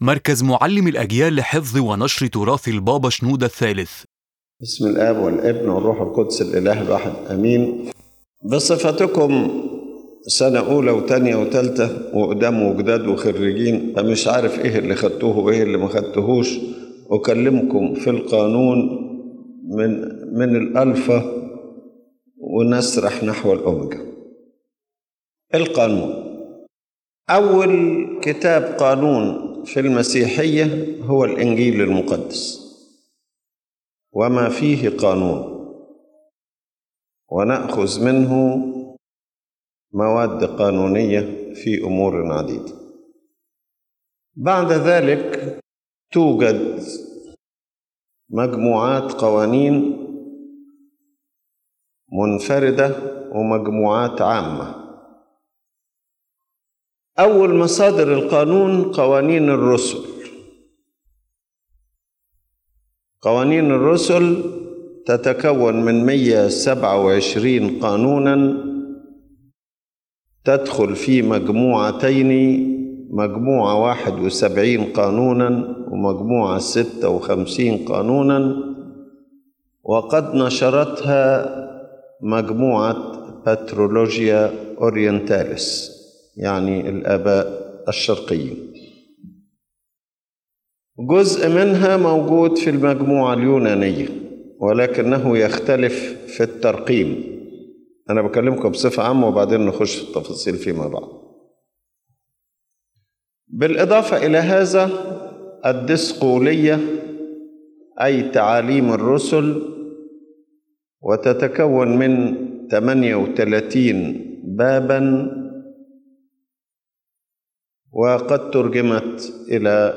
The lecture addresses the canons of the Ecumenical Councils recognized by the Coptic Orthodox Church (Nicaea, Constantinople, Ephesus), as well as local councils such as Ancyra, Antioch, and Carthage, noting that forged canons were especially attributed to the Council of Nicaea.